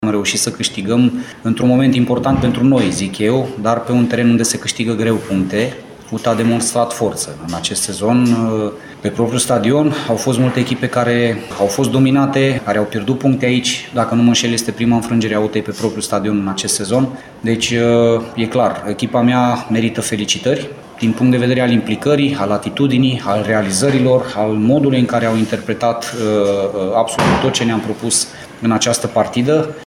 Laszlo Balint a spus că s-a simțit ciudat intrând la stadion în vestiarul oaspeților, dar în timpul jocului s-a concentrat pe ceea ce are de făcut actuala lui echipă, pe care a felicitat-o pentru victoria externă foarte importantă de la Arad:
Laszlo-Balint-o-victorie-importanta-pt-Otelul.mp3